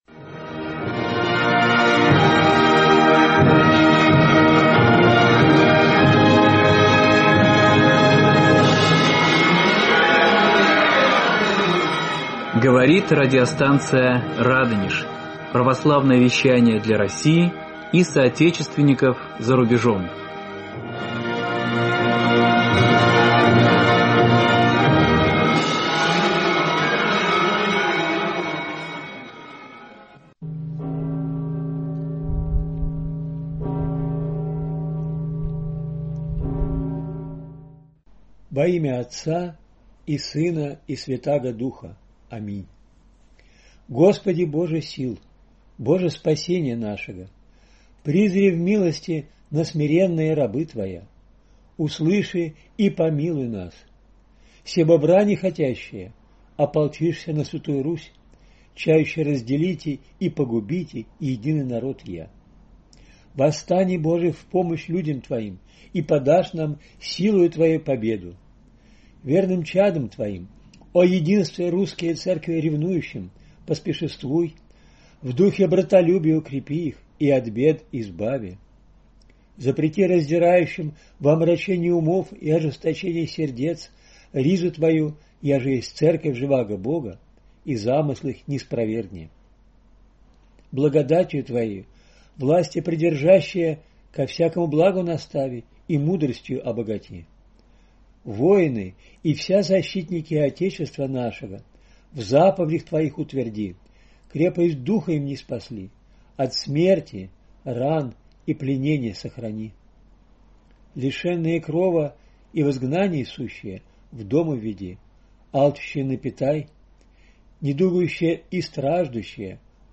В эфире беседа